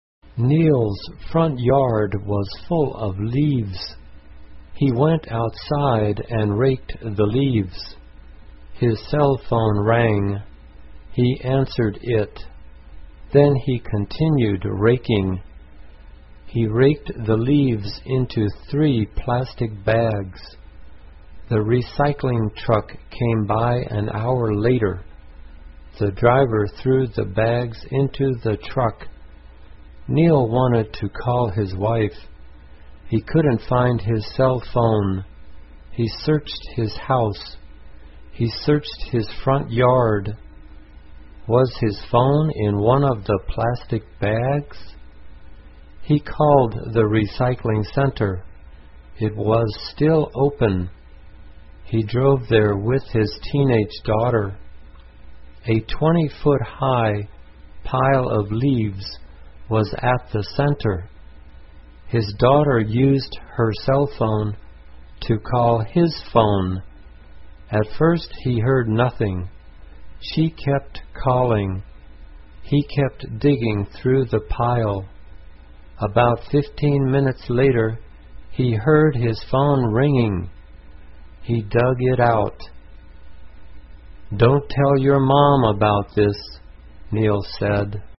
慢速英语短文听力 响了！响了！